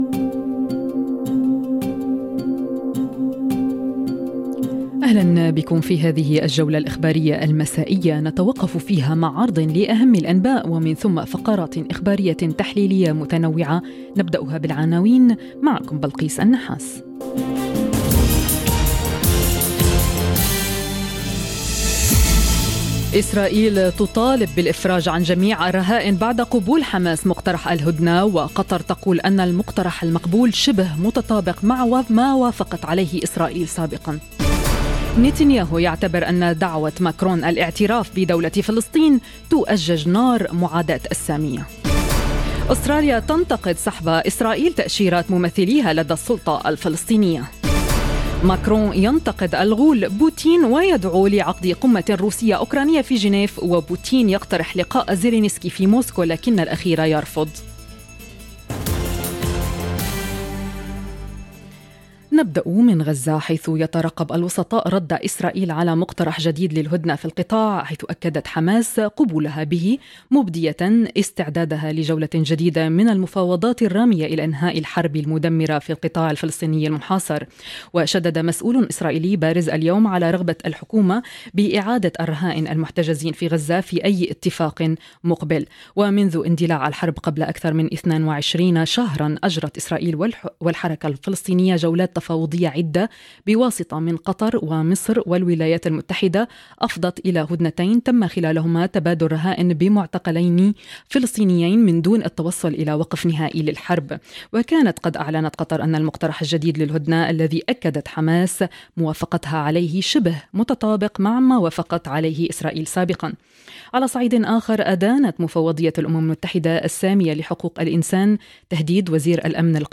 نشرة أخبار المساء: إسرائيل تطالب بالرهائن وسط هدنة، توتر دبلوماسي، وماكرون يصعّد ضد بوتين - Radio ORIENT، إذاعة الشرق من باريس